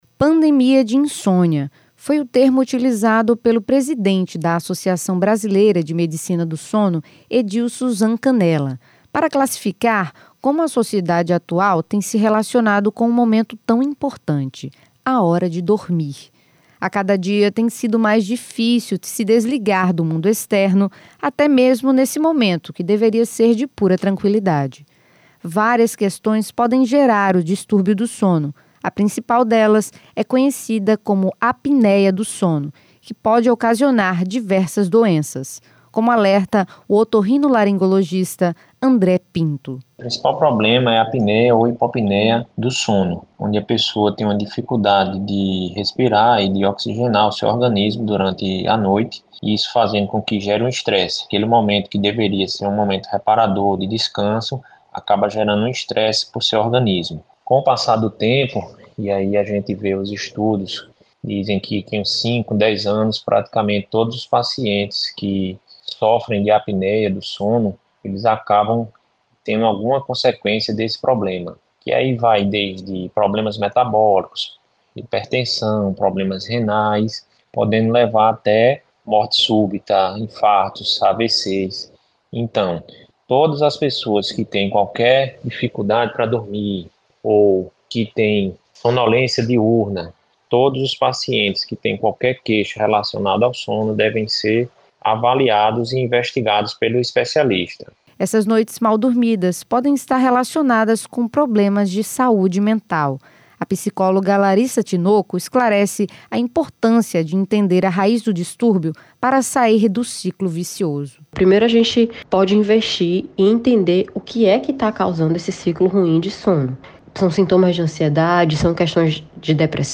Reportagem: problemas na qualidade do sono